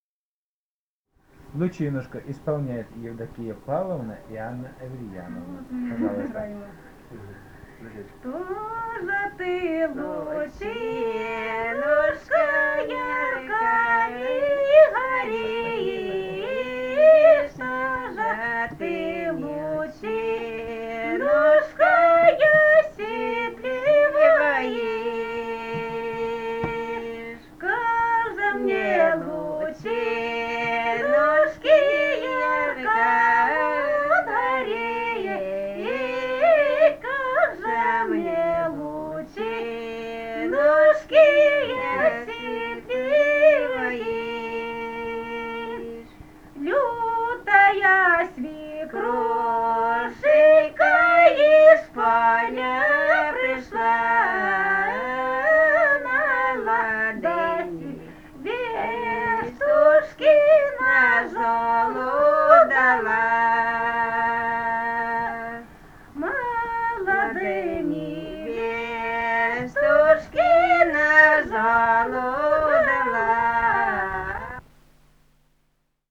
полевые материалы
«Что же ты, лучинушка» (лирическая).
Ставропольский край, пос. Терек Прикумского (Будённовского) района, 1963 г. И0717-15